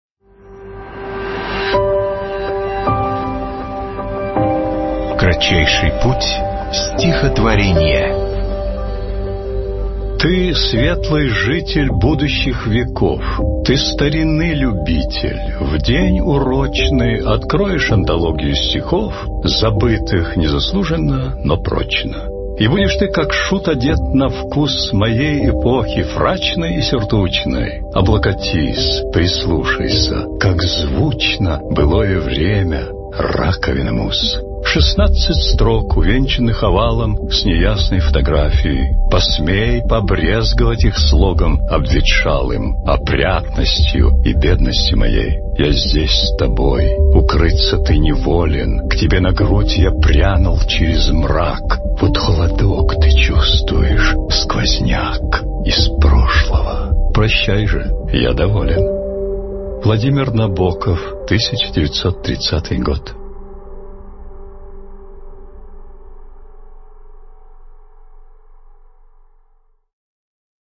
1. «Владимир Набоков – Неродившемуся читателю (читает Николай Мартон)» /
Nabokov-Nerodivshemusya-chitatelyu-chitaet-Nikolay-Marton-stih-club-ru.mp3